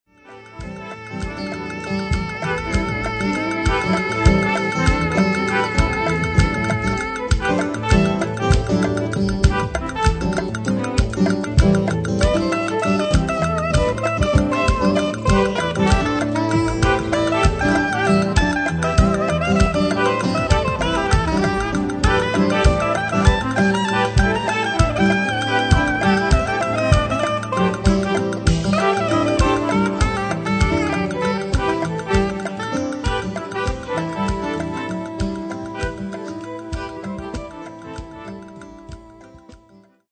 and bass, guitars, keyboard, sax and trumpet.